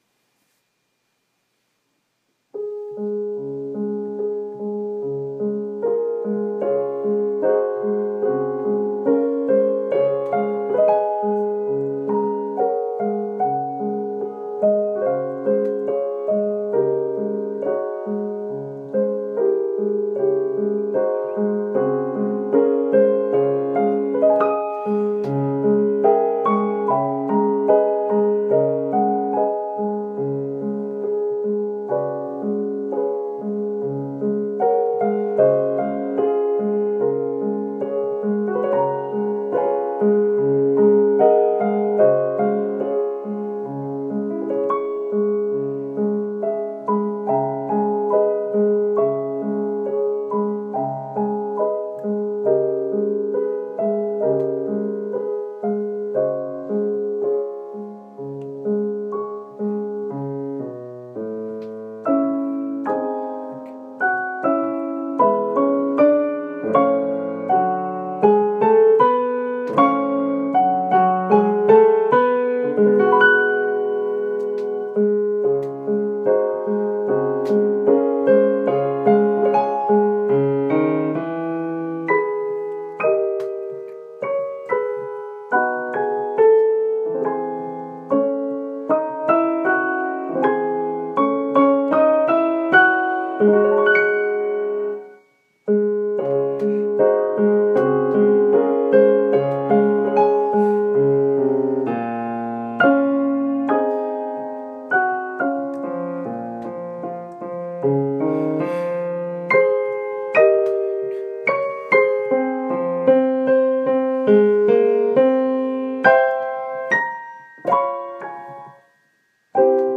As penance (pretty pleasurable penance, actually), here’s an iPhone recording of me playing Vītols’ op. 8 Berceuse